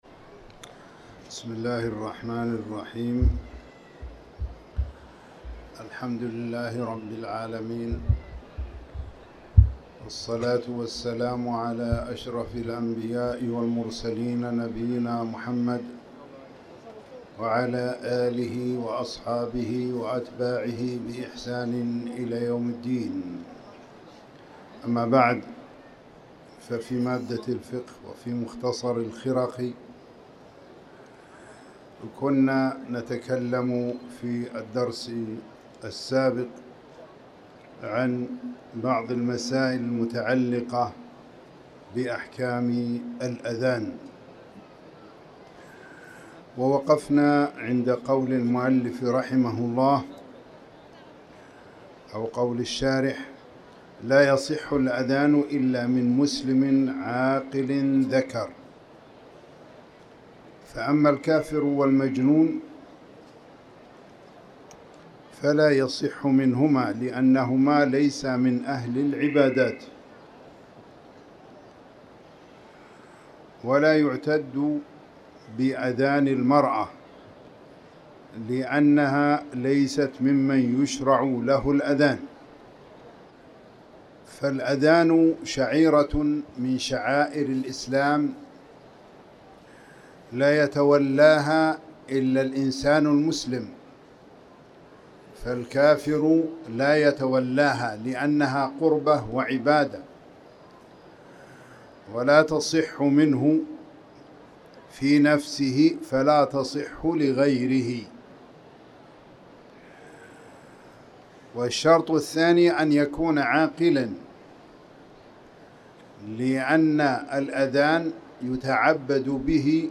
تاريخ النشر ١٠ جمادى الأولى ١٤٤٠ هـ المكان: المسجد الحرام الشيخ